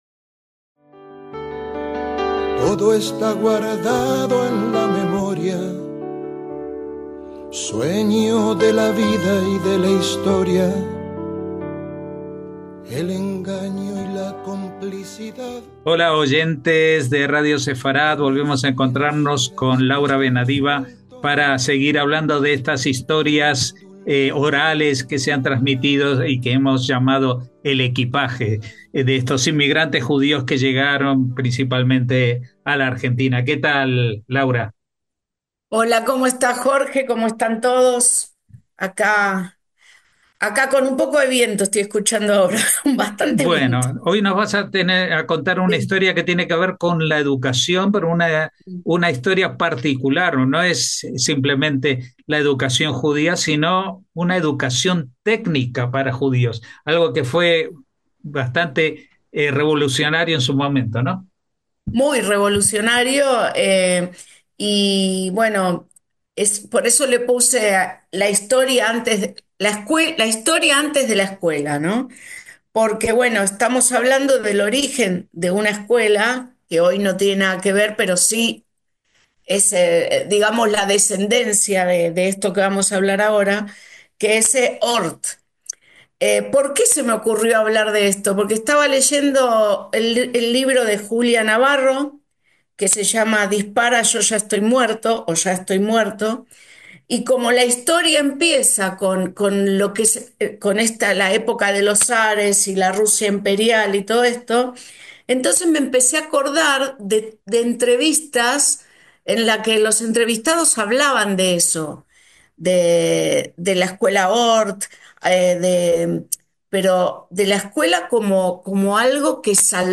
EL EQUIPAJE: HISTORIA ORAL DE EMIGRANTES JUDÍOS
Escuchamos las voces que vivieron la transición de la 'escuela de artes y oficios' ORT al referente educativo que conocemos hoy.